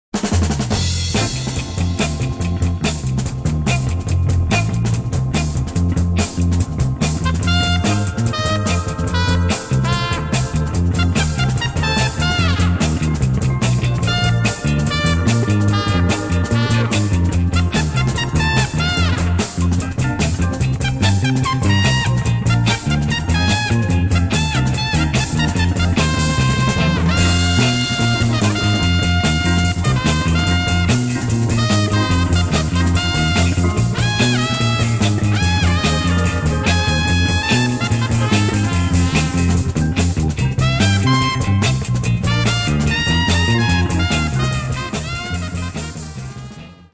Poliziesco - Police Film - Polizeifilm exciting fast instr.